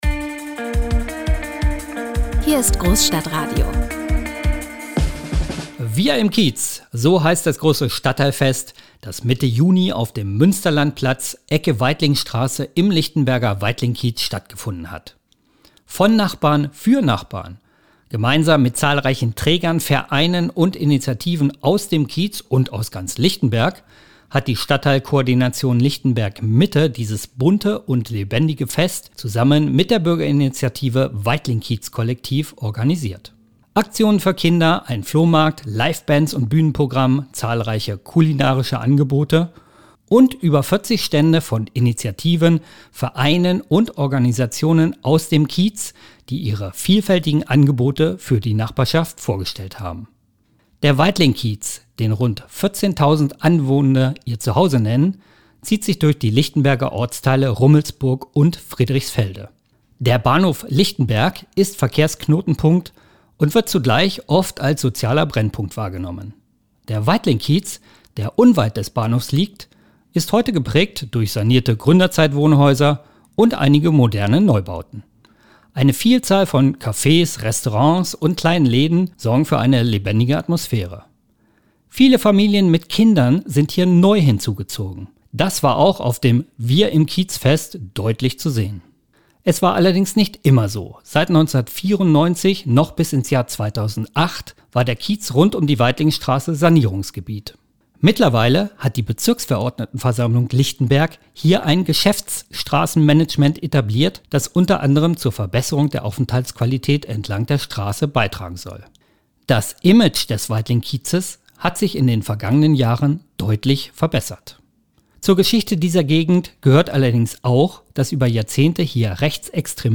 Beschreibung vor 9 Monaten „Wir im Kiez“ – so heißt das große Stadtteilfest, das Mitte Juni auf dem Münsterlandplatz, Ecke Weitlingstraße im Lichtenberger Weitlingkiez stattgefunden hat.
Podcast: Interviews, Gespräche und Meinungsäußerungen von Vertreter:innen dieser Organisationen, Vereine und Einrichtungen: